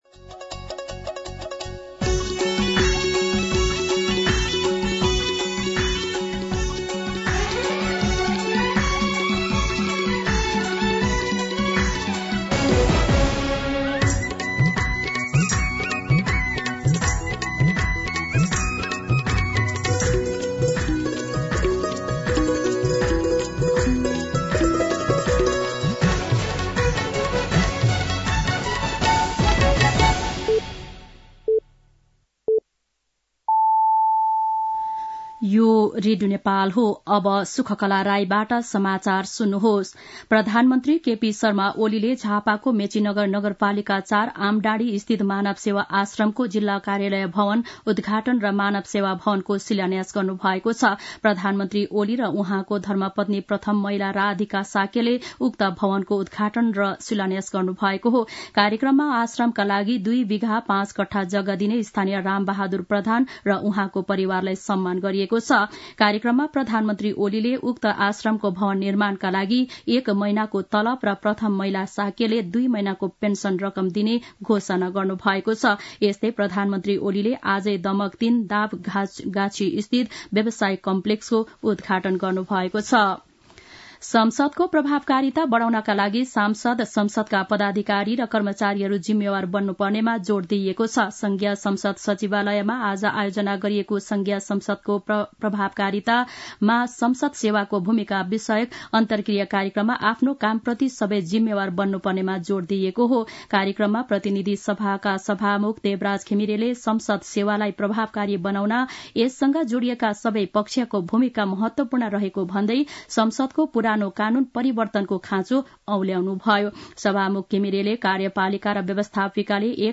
दिउँसो ४ बजेको नेपाली समाचार : २४ मंसिर , २०८१
4pm-Nepali-News-23.mp3